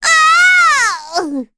Requina-Vox_Dead_kr.wav